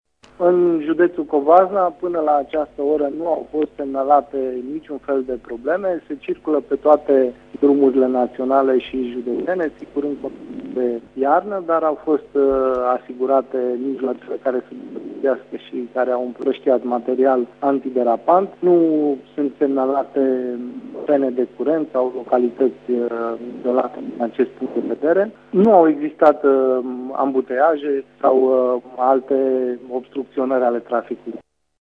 În Covasna se circulă în condiţii de iarnă, a declarat prefectul Sebastian Cucu: